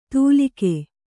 ♪ tūlike